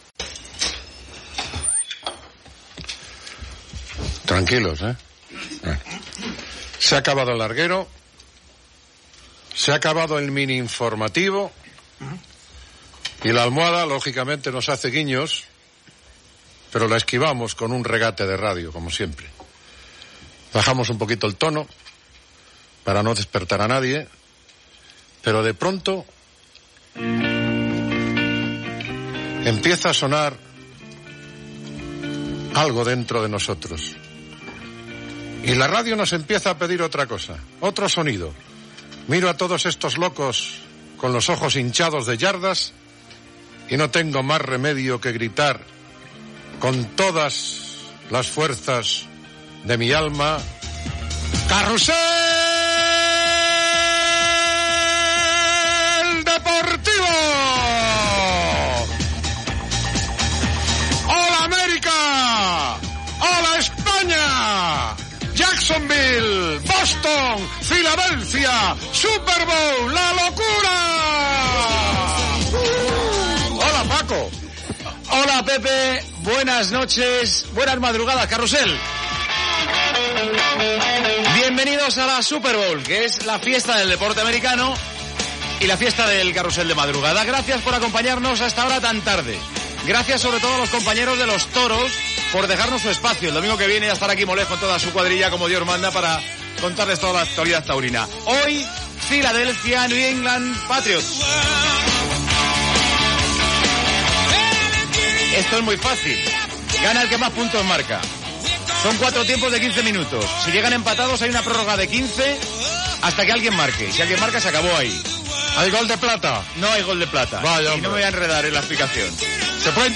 b506eb1c9b07158e9b958436d0a101996c41d2cb.mp3 Títol Cadena SER Emissora Ràdio Barcelona Cadena SER Titularitat Privada estatal Nom programa Carrusel deportivo Descripció Edició especial per transmetre la final de la XXXIX Super Bowl des de l' Alltel Stadium de Jacksonville (EE.UU) entre New England Patriots i Philadelphia Eagles. Presentació, regles de l'esport, connexió amb EE.UU., prsentaciò de l'equip, informació de com ha estat la prèvia del partit, connexió amb Madrid i Barcelona, invitació a enviar missatges al programa, cançó del Carrusel de la SER, informació del partit.
Gènere radiofònic Esportiu